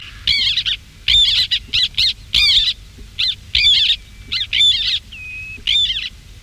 Guifette noire
Chlidonias niger